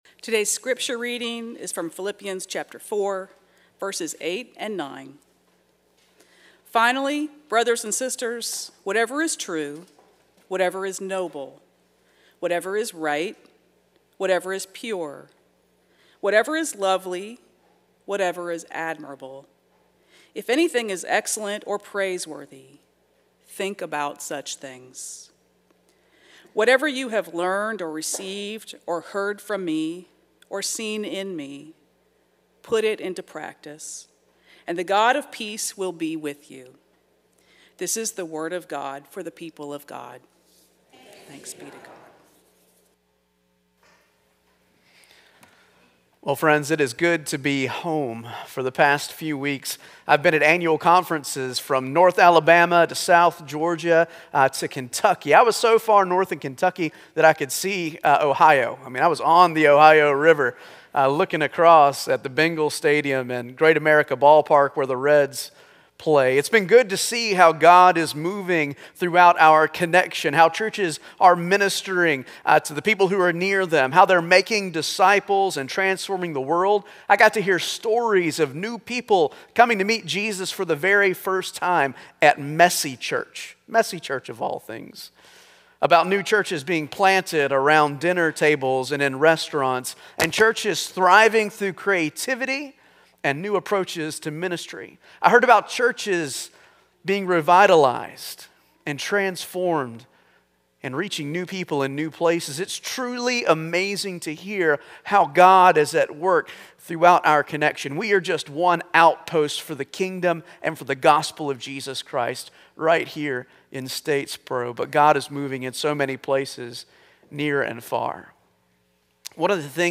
Passage: Exodus 14: 16-18, 21-22 Service Type: Traditional Service